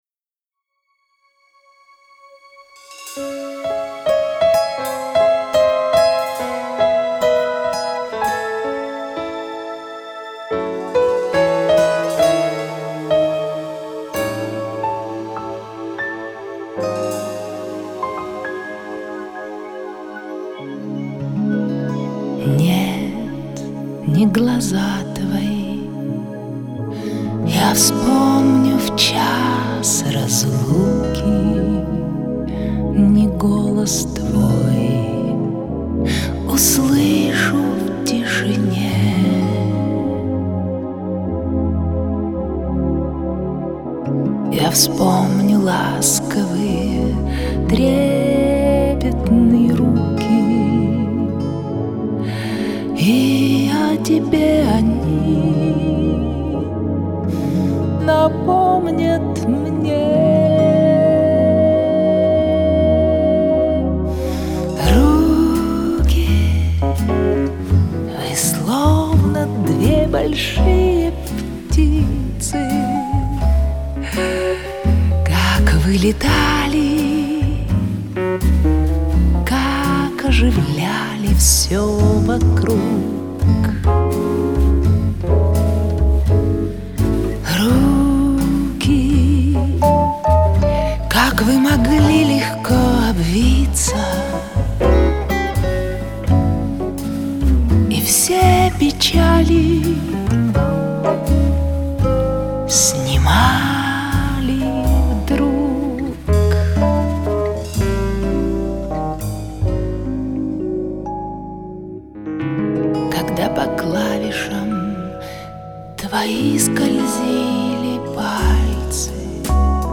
Добавлю красивый романс